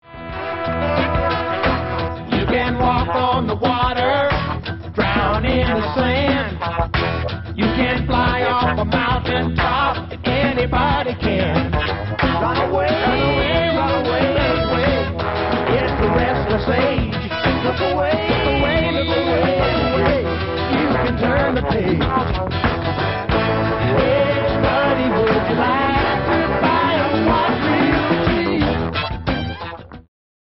historical country rock, mixed with gospel and blues